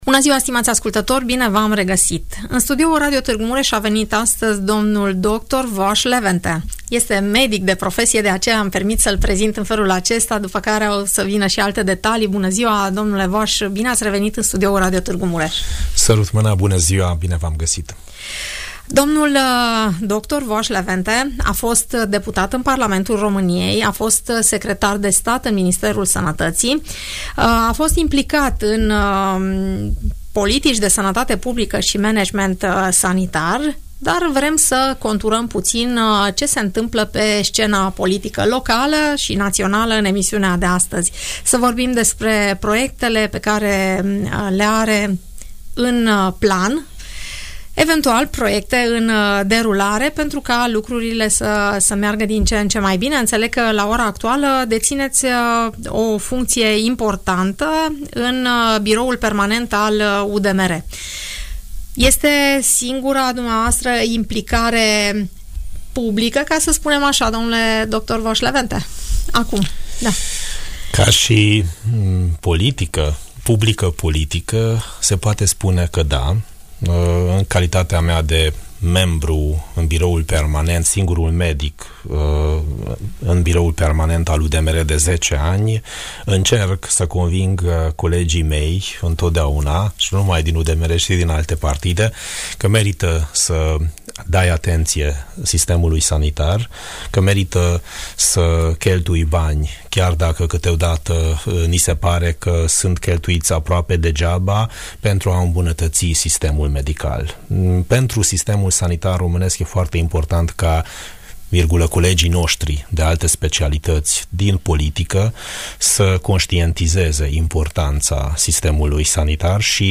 Fostul secretar de stat în Ministerul Sănătății, dl dr. Vass Levente, vorbește la Radio Târgu Mureș , despre părțile bune sau mai puțin bune ale sistemului sanitar românesc și despre ceea ce ar trebui făcut pentru ca pacienții să aibă parte de îngrijire medicală la nivel european.